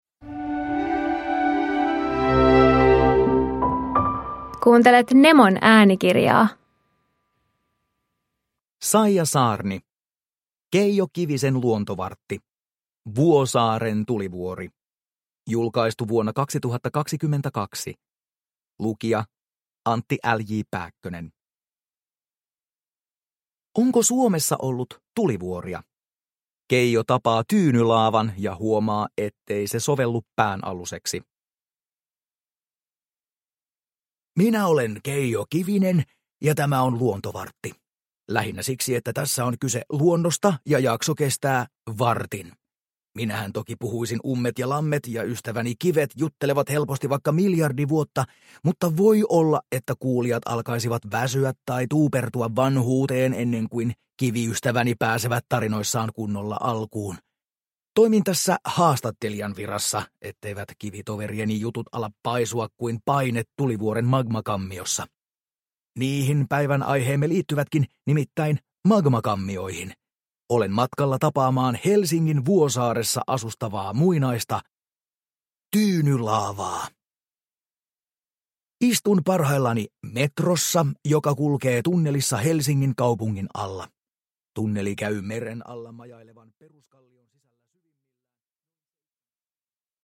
Vuosaaren tulivuori – Ljudbok – Laddas ner